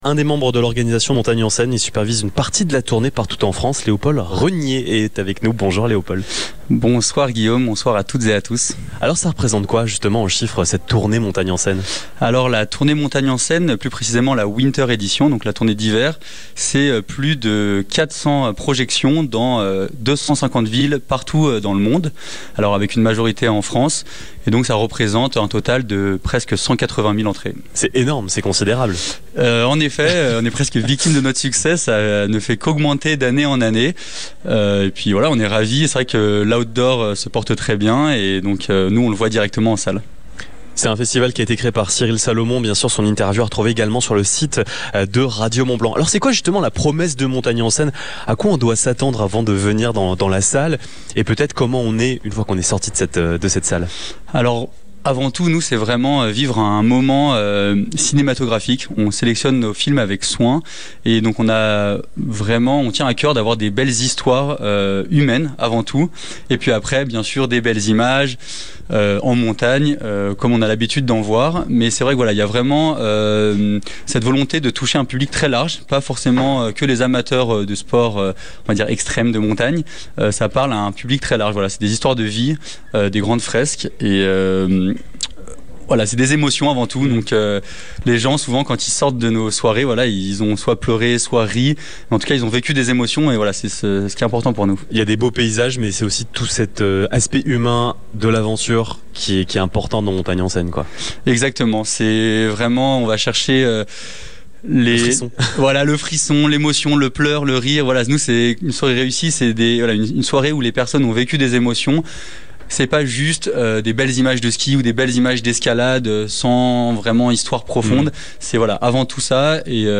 Ce mercredi 26 novembre, nous avons posé nos micros au Ciné Mont-Blanc, le multiplex emblématique de la vallée, à l'occasion du Festival Montagne en Scène.
Interview